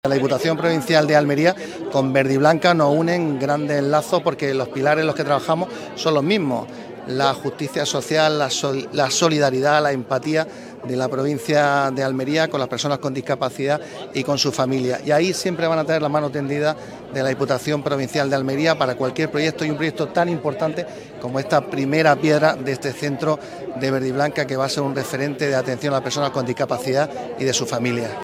ANGEL-ESCOBAR-VICEPRESIDENTE-DIPUTACION-PRIMERA-PIEDRA-CENTRO-VERDIBLANCA.mp3